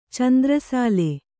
♪ candra sāle